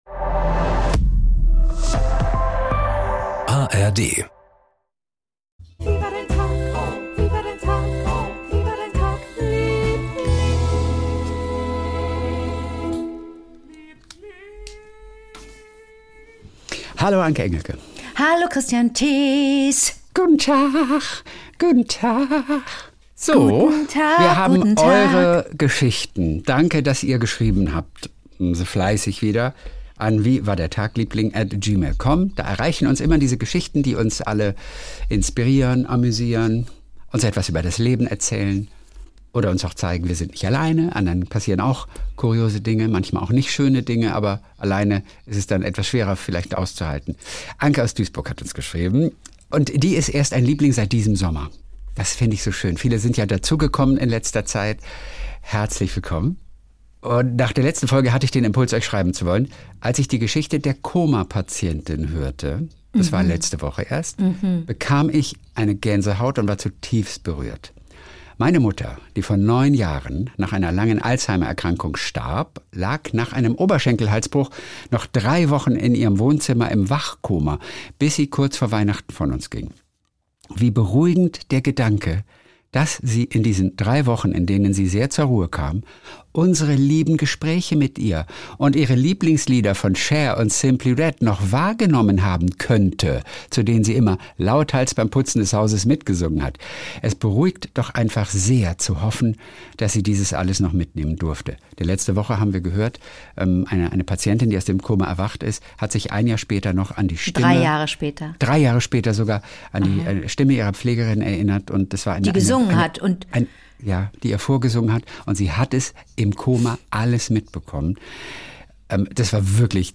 (Hörererektionen) 36:28 Play Pause 6h ago 36:28 Play Pause Reproducir más Tarde Reproducir más Tarde Listas Me gusta Me gusta 36:28 Jeden Montag und Donnerstag Kult: SWR3-Moderator Kristian Thees ruft seine beste Freundin Anke Engelke an und die beiden erzählen sich gegenseitig ihre kleinen Geschichtchen des Tages.